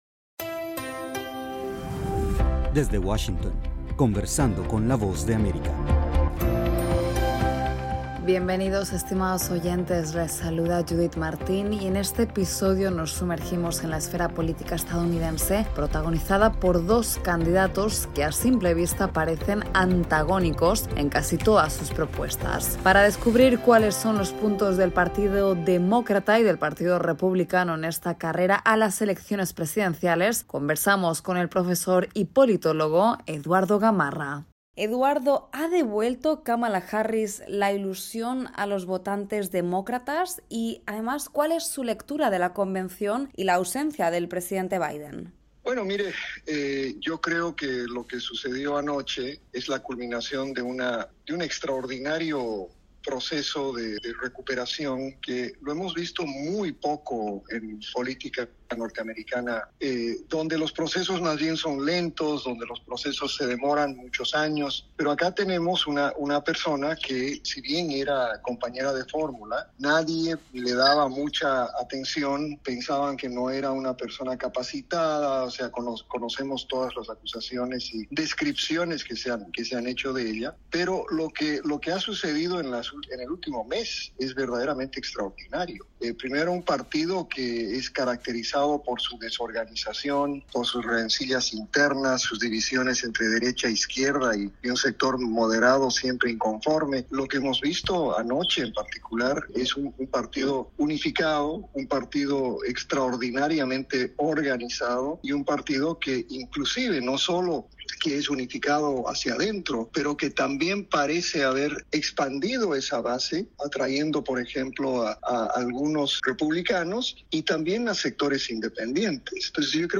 AudioNoticias
desde la Voz de América en Washington DC